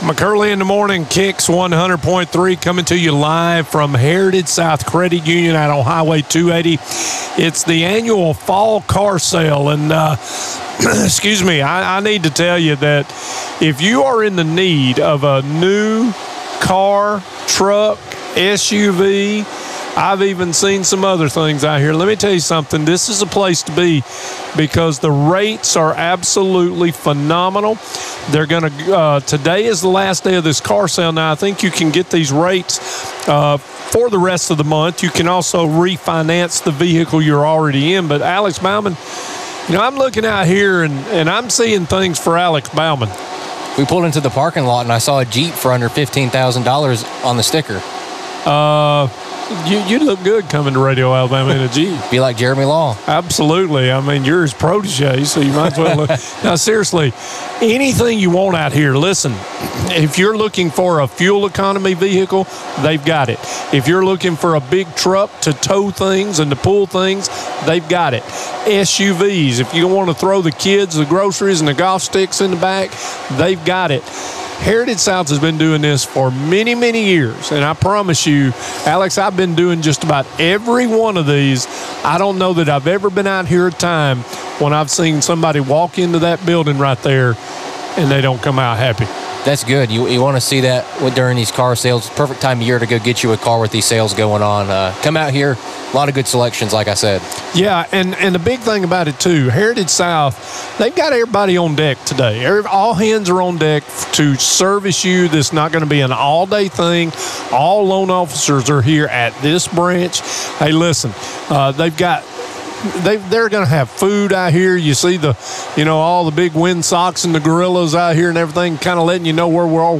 Live from Heritage South Credit Union's 7th Annual Fall Car Sale